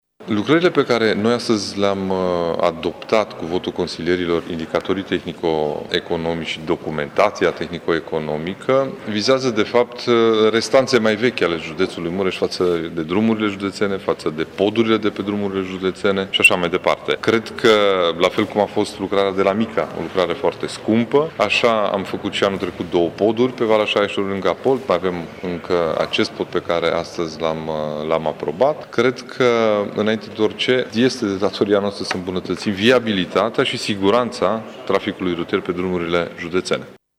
Preşedintele CJ Mureş, Ciprian Dobre, a arătat că lucrările au ca scop îmbunătăţirea infrastructurii rutiere a judeţului: